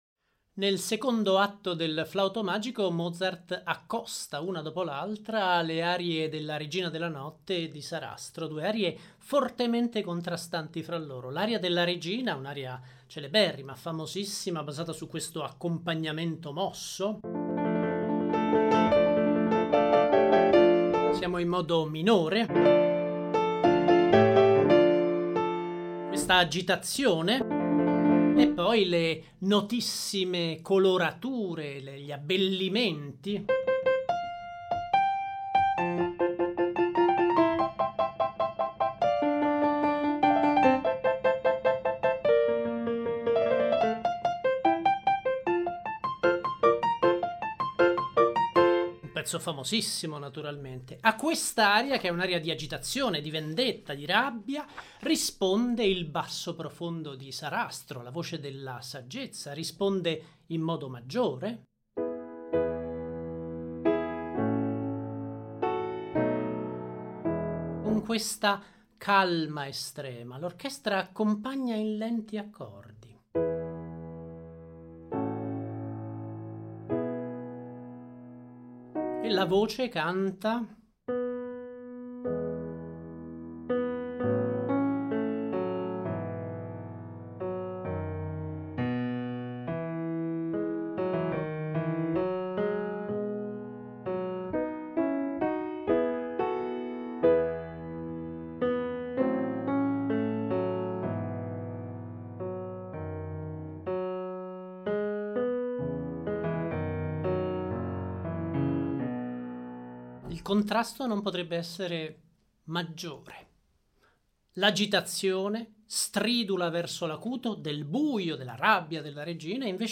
W.A. Mozart: Die Zauberflöte, K. 620, confronto tra le Arie della Regina della notte e di Sarastro nel secondo atto; la gradazione stilistica dei primi cinque numeri dell’opera: Ouverture, Introduzione, Aria di Papageno, Aria di Tamino, Aria della Regina della notte: